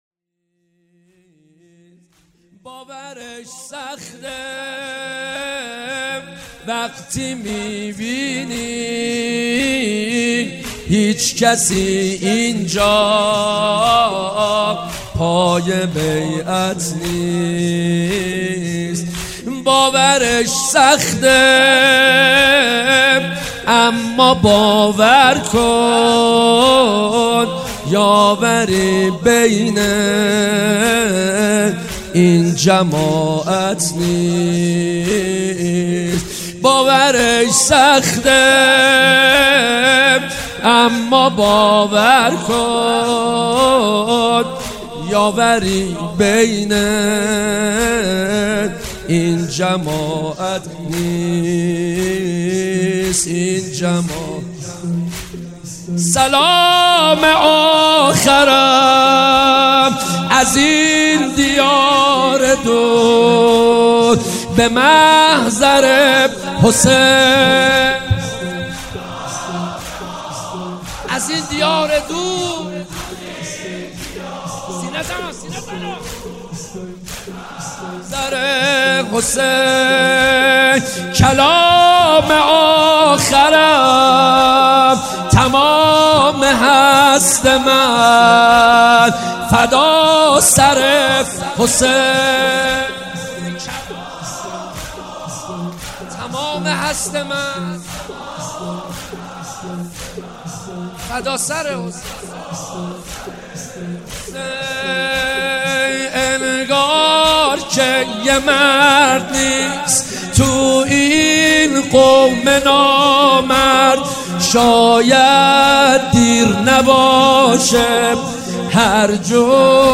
شب اول محرم 98